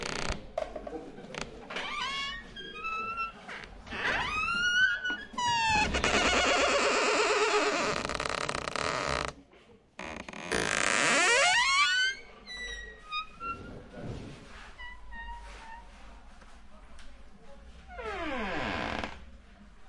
西非 " 木门打开关闭时非常吱吱作响摇摇欲坠
描述：门木打开关闭非常吱吱作响
Tag: 关闭 吱吱作响 打开